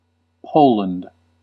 Ääntäminen
Ääntäminen US UK : IPA : /ˈpəʊ.lənd/ US : IPA : /ˈpoʊ.lənd/ Lyhenteet ja supistumat (laki) Pol.